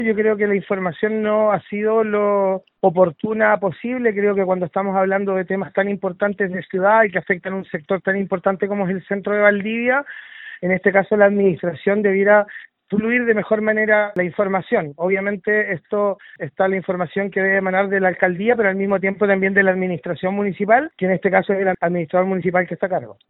Por su parte, el concejal del Partido Socialista, Lucio Sanhueza, criticó la falta de información completa y oportuna desde la administración municipal a los concejales, esto ante un tema de tal envergadura.